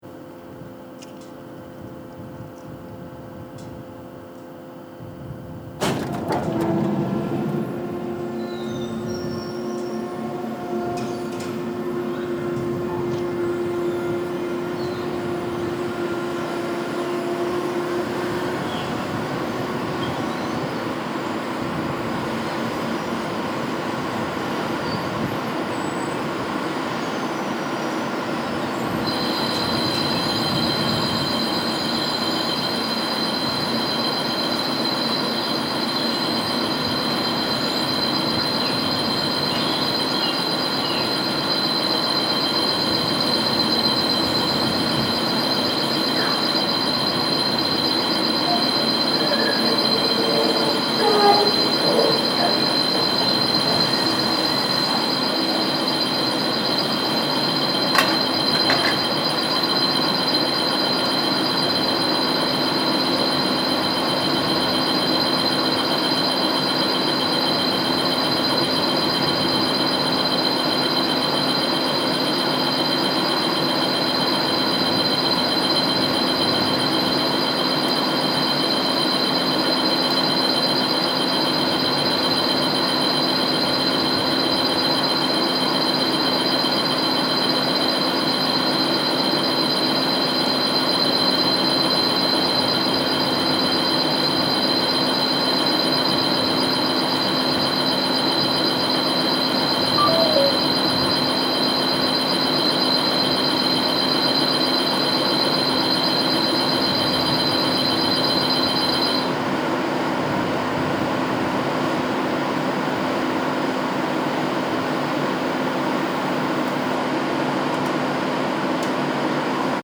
Soundscape: La Silla NTT dome opening
The gates of the NTT dome open. The beep is an alarm to keep birds away from the telescope's mirror once it is exposed.
Soundscape Mono (wav)
ss-ls-ntt-dome-op_mono.mp3